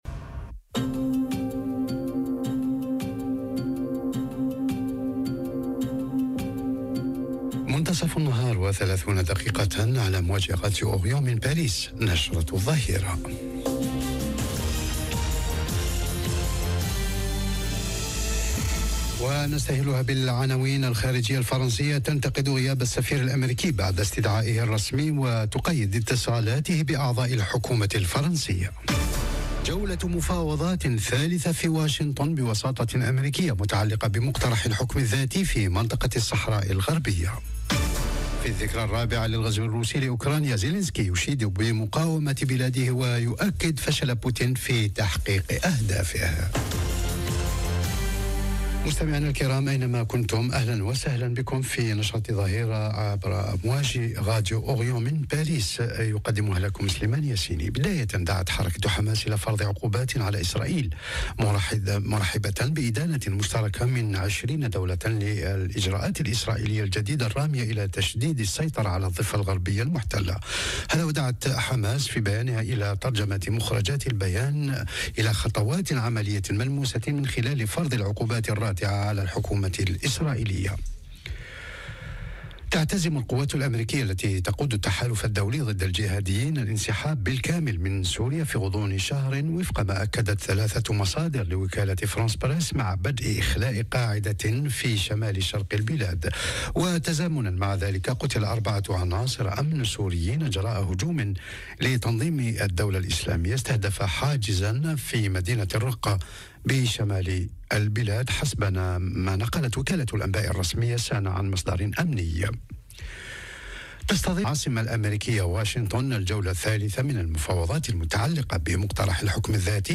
نشرة الظهيرة.. معاقبة سفير أمريكا بفرنسا ومفاوضات بشأن الصحراء - Radio ORIENT، إذاعة الشرق من باريس